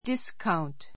discount dískaunt ディ ス カウン ト 名詞 割引 a discount store a discount store 割引店 I bought a watch at a 15 percent discount.